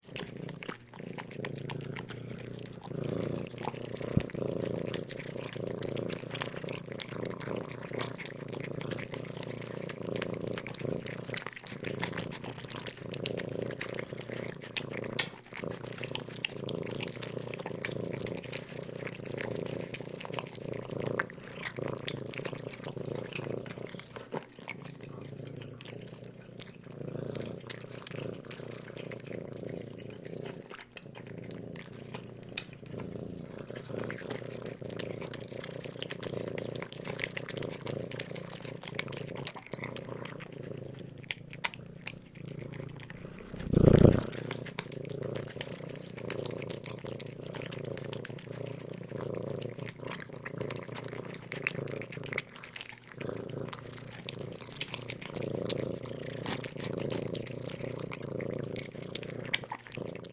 Звук мурчащего котенка с лаканием молочка